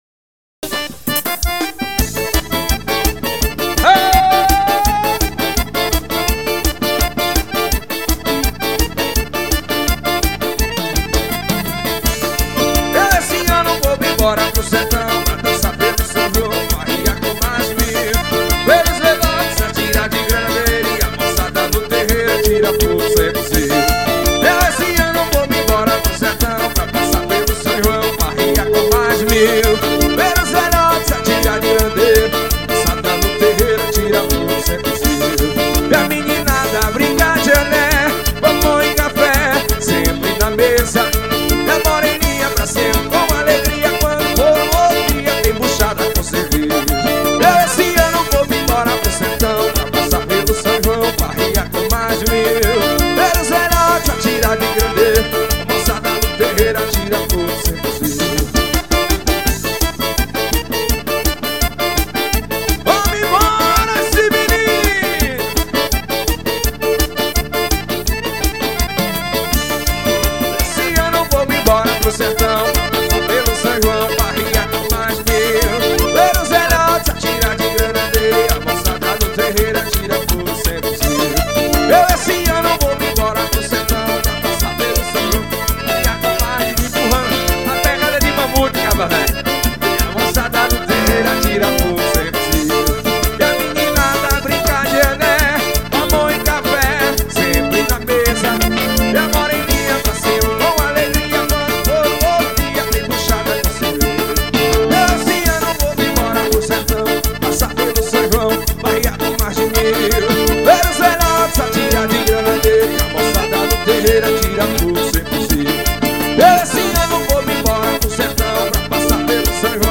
Forró.